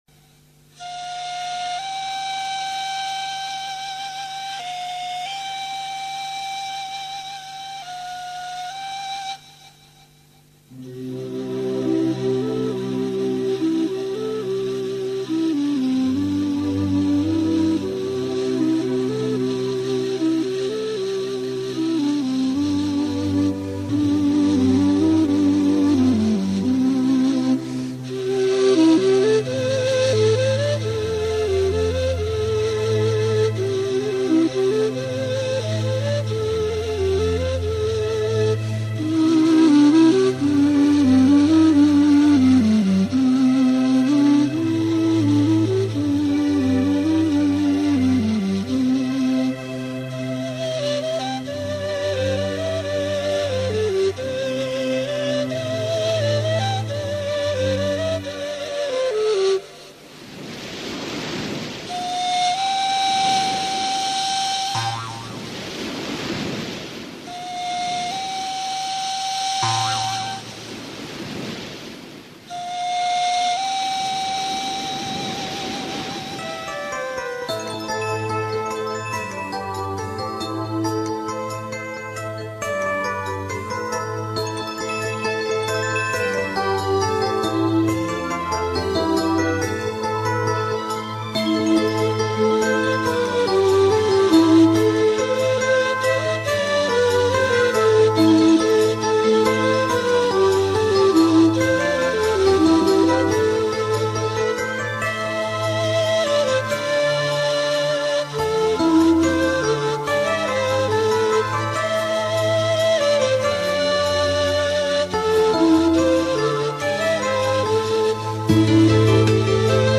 Здесь переход практически не заметным получился